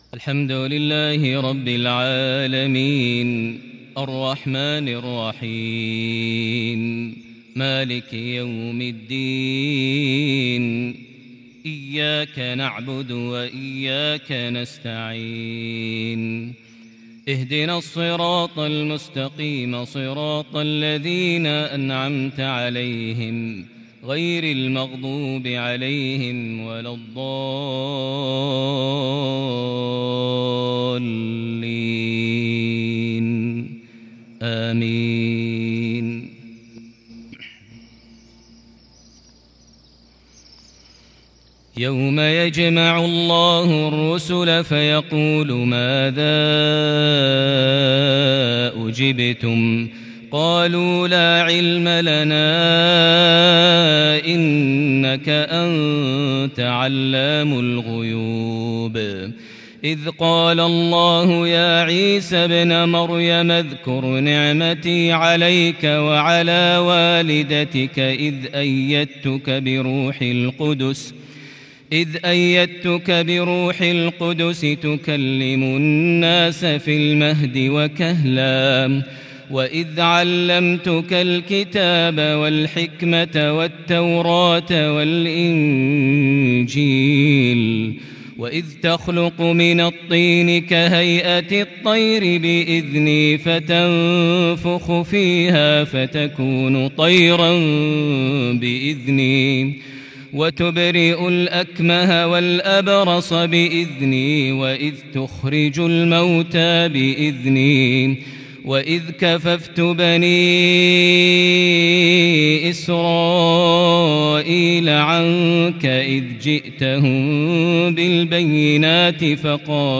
فجرية تحبيرية متقنة خاشعة لخواتيم سورة المائدة (109-120) | الثلاثاء 20 شوال 1442هـ > 1442 هـ > الفروض - تلاوات ماهر المعيقلي